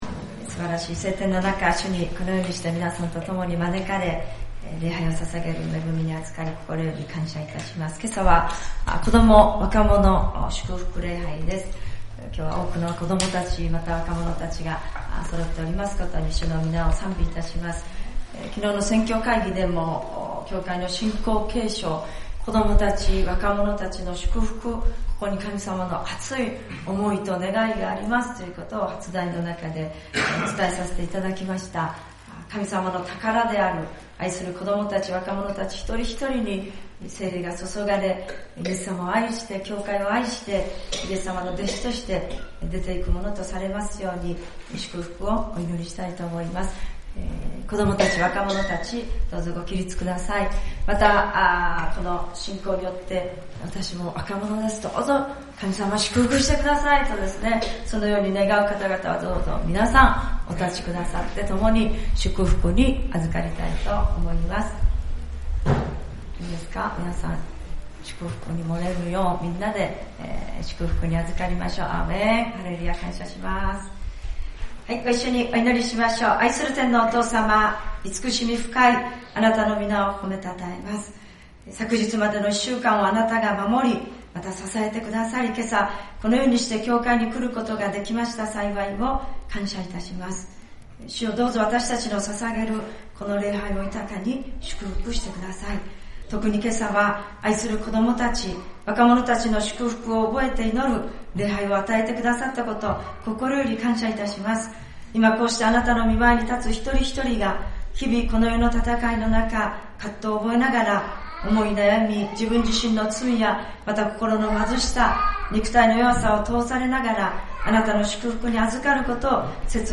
聖日礼拝「世界宣教のまぼろし